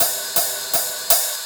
Hats 10.wav